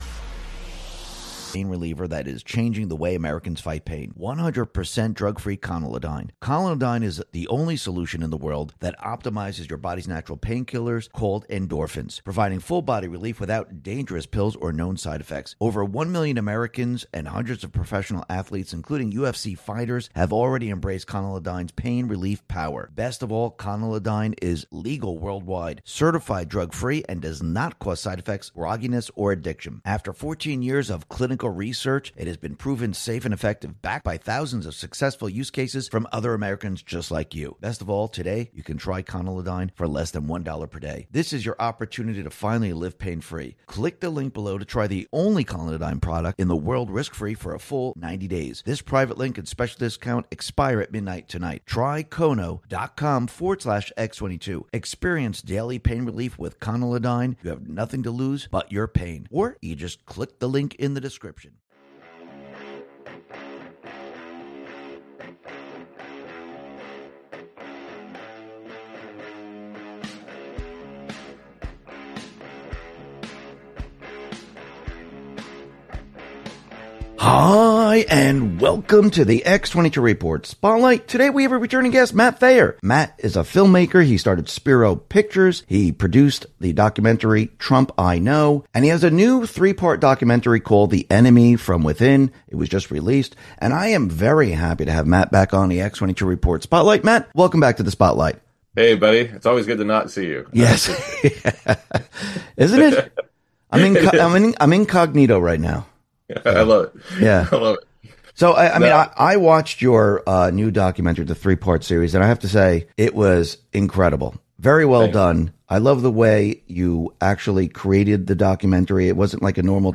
SPREAD THE WORD MP3 Audio Summary ➡ Conalodyne Collidine is a pain reliever that boosts your body’s natural painkillers, endorphins, providing relief without side effects or addiction.